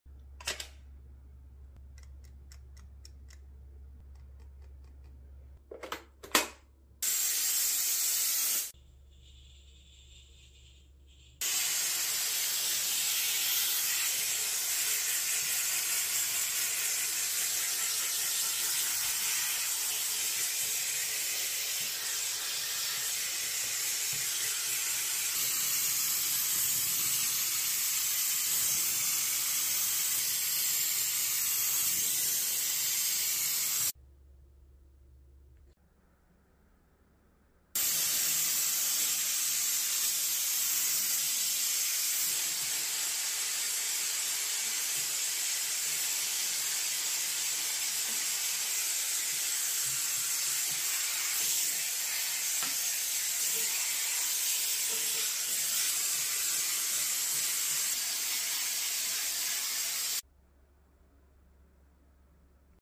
girls cleaning sink trend steam sound effects free download
girls cleaning sink trend steam cleaning asmr housewife lifestyle